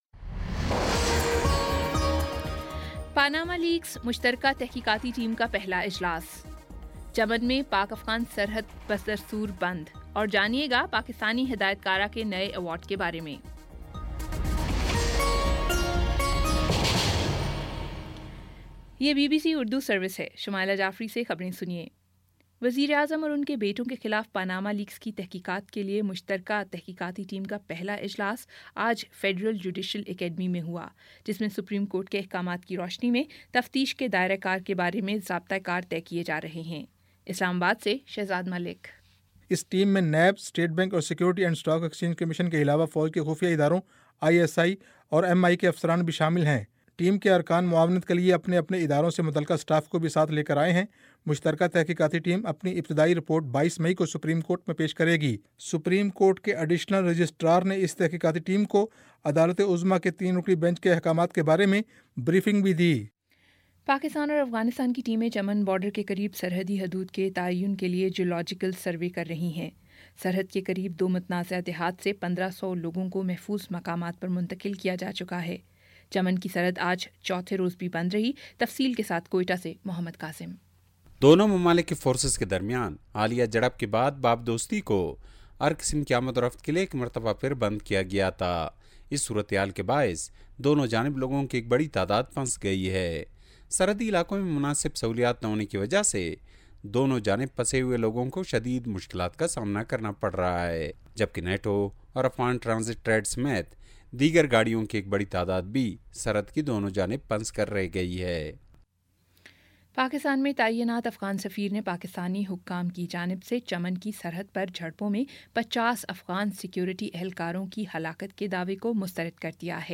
مئی 08 : شام پانچ بجے کا نیوز بُلیٹن